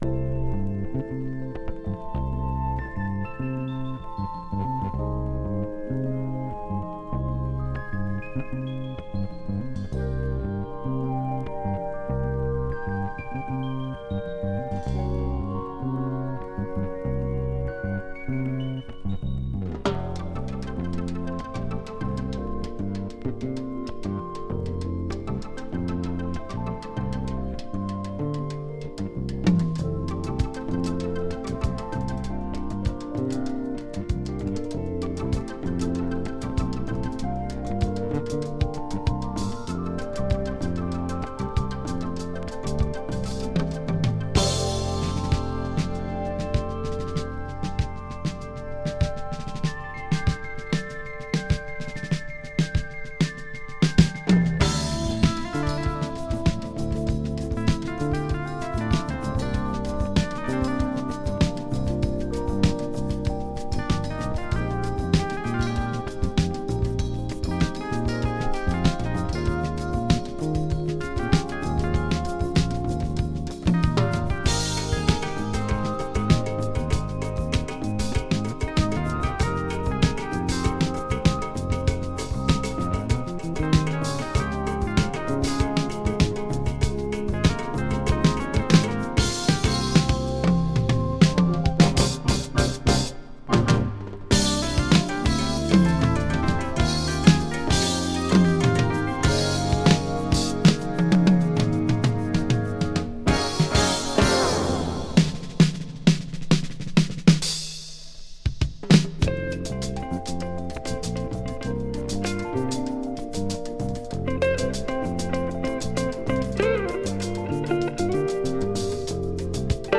JAPANESE LATIN FUSION!